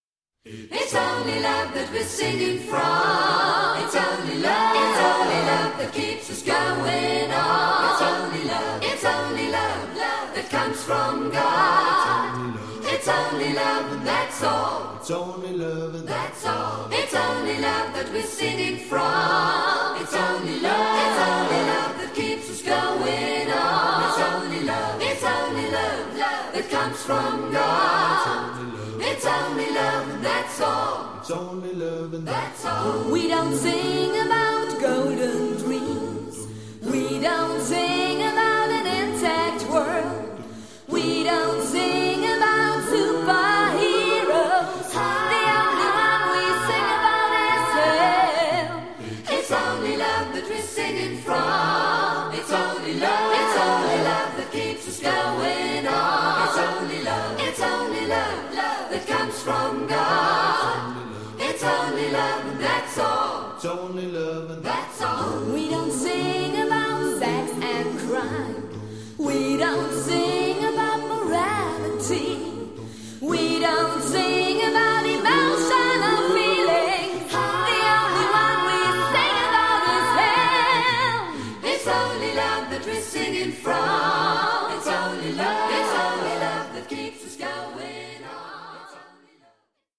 rockige Sounds